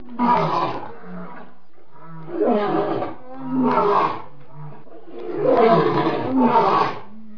دانلود صدای حیوانات جنگلی 106 از ساعد نیوز با لینک مستقیم و کیفیت بالا
جلوه های صوتی